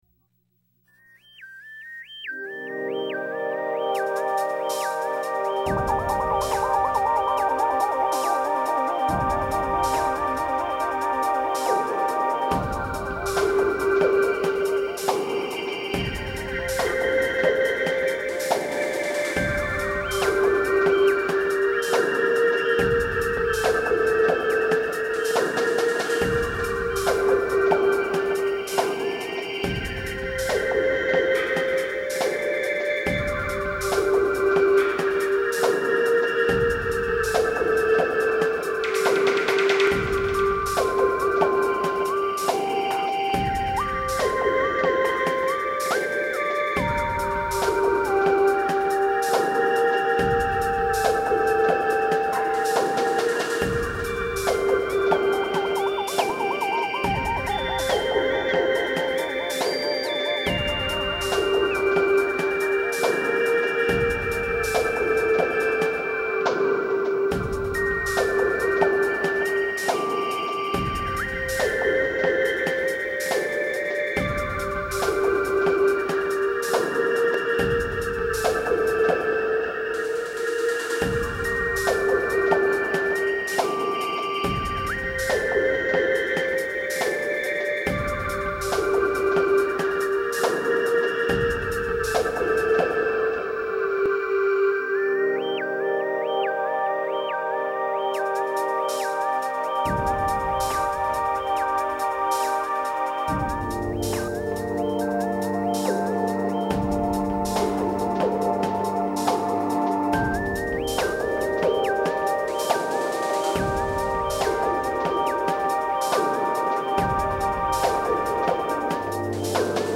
bass, featuring the Korg-DS10